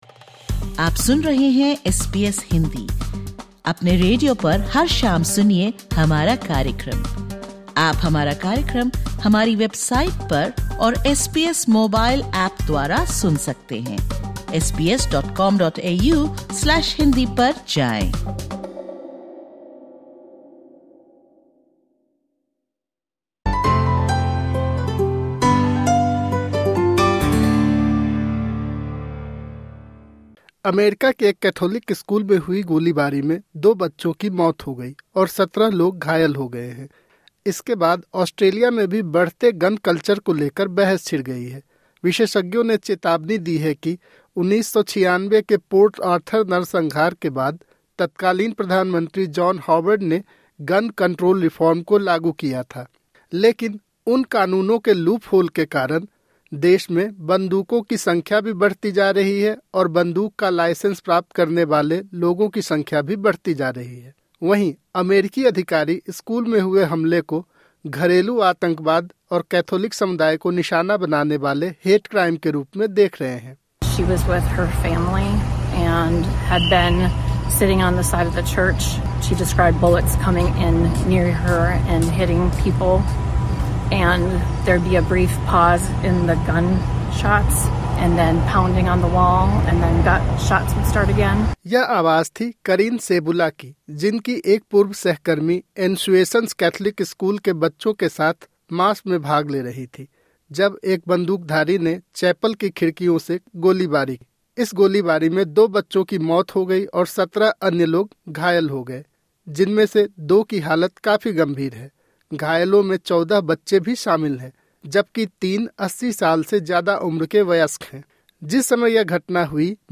(Disclaimer: The information given in this interview is of a general nature.